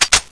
boltpull.wav